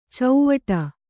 ♪ cauvaṭa